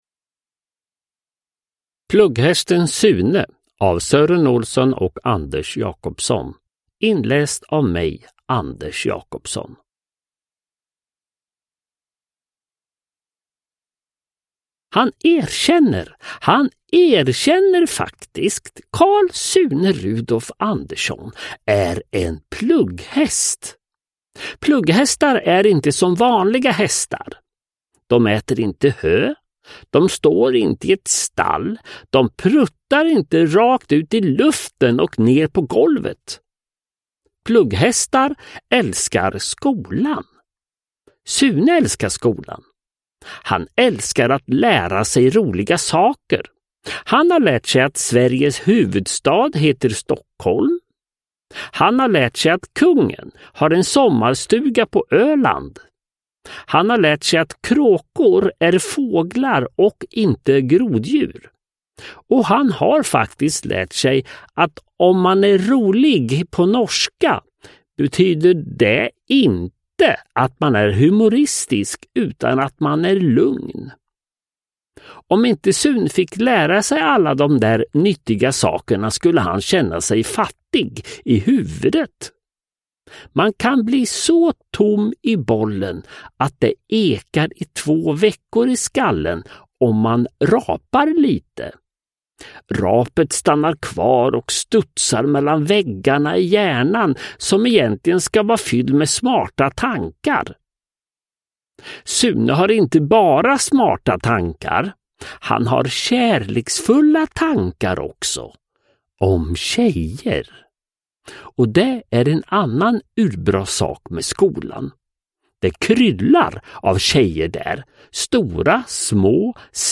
Plugghästen Sune – Ljudbok – Laddas ner
Uppläsare: Anders Jacobsson